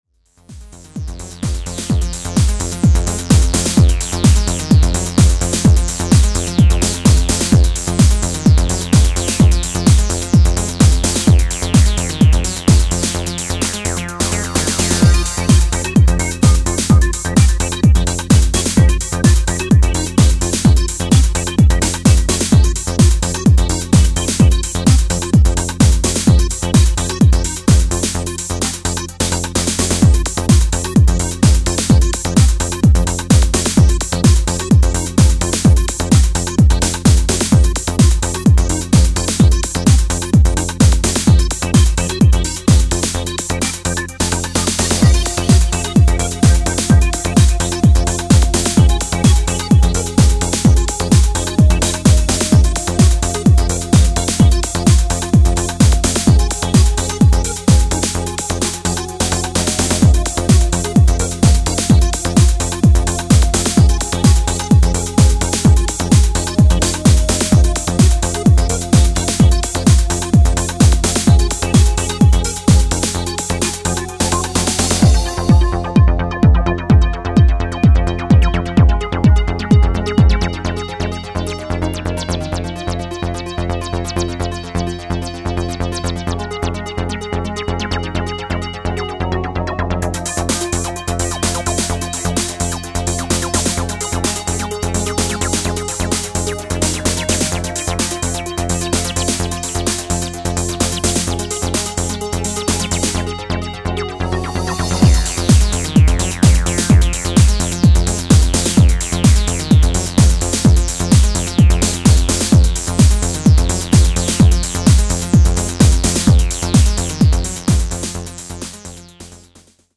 切れ味鋭いフレージングと909キックをハードに鳴らす